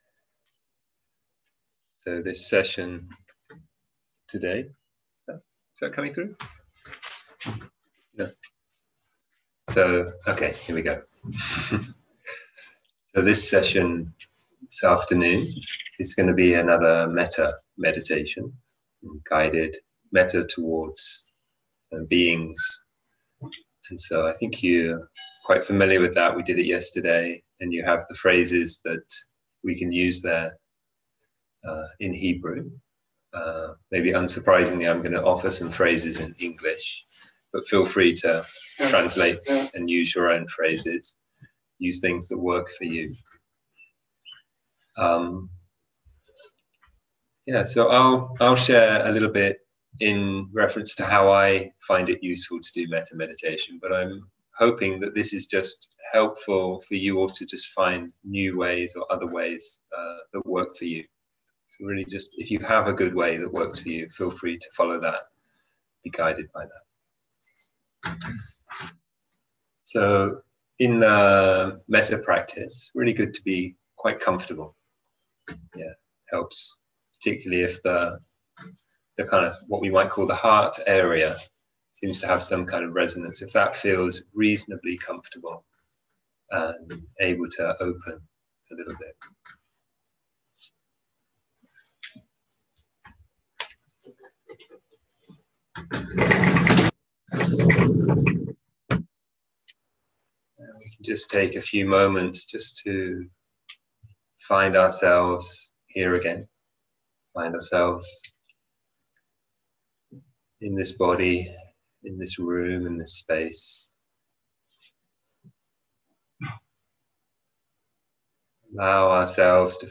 מדיטציה מונחית - מטא כולל למערכת יחסים ניטרלית
סוג ההקלטה: מדיטציה מונחית